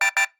alarm Mixdown 5.mp3